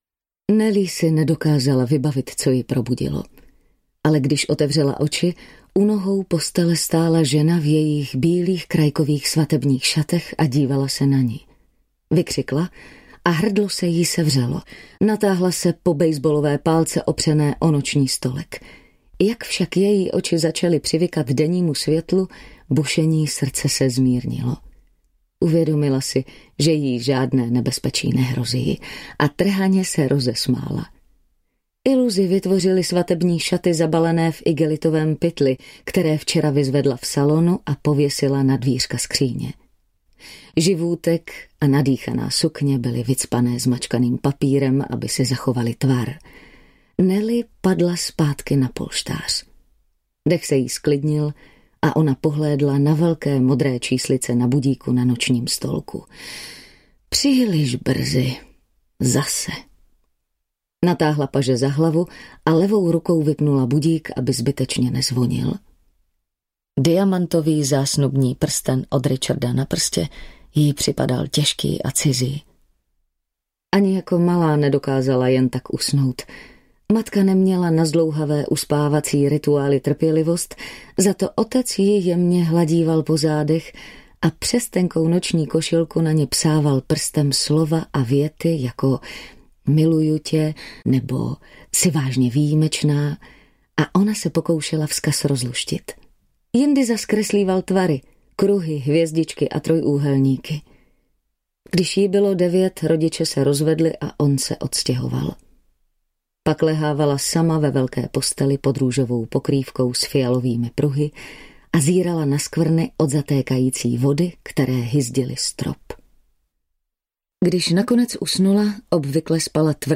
Ukázka z knihy
manzelka-mezi-nami-audiokniha